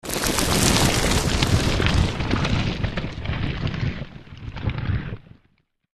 Звуки камней
Камни лавиной несутся с горы